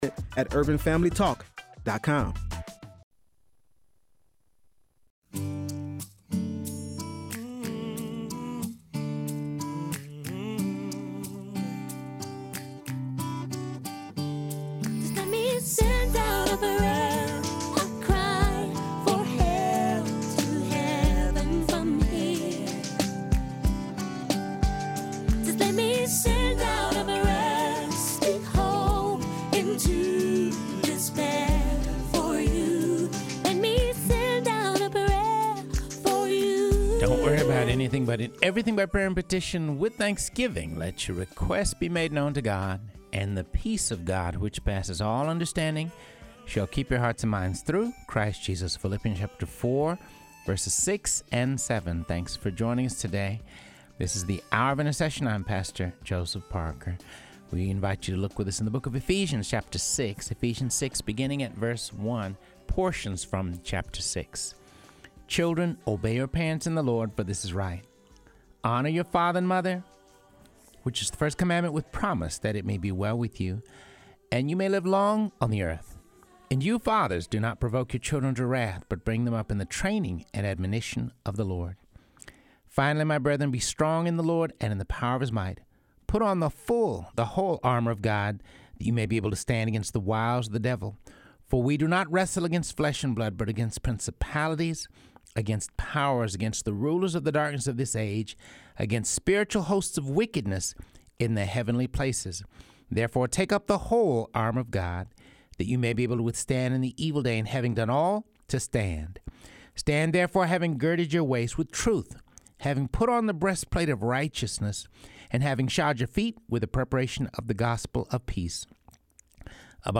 is joined via phone